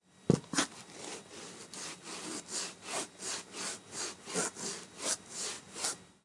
描述：两个朋友正在用锤子和一种打磨机清除一条铁船上的铁锈。我站在他们旁边，用我的Edirol R09记录他们发出的噪音。
Tag: 现场录音 性质 噪音 冲击 工具 工作